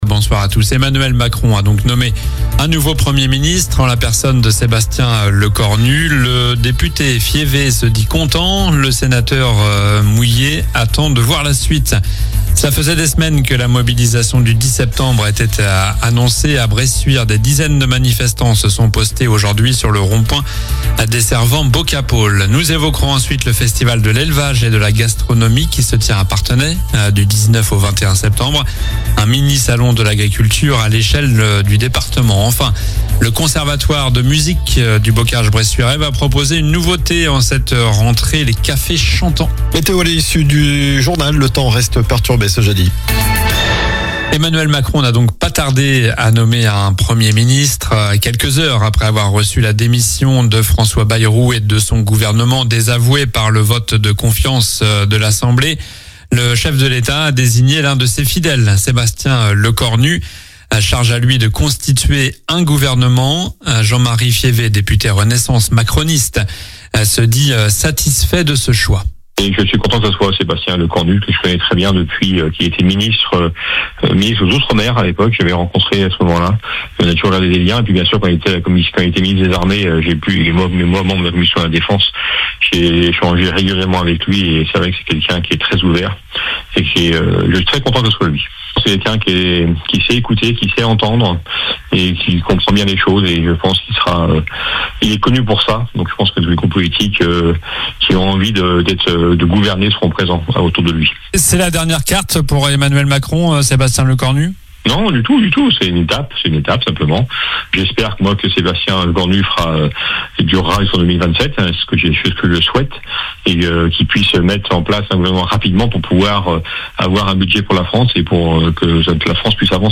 Journal du mercredi 10 septembre (soir)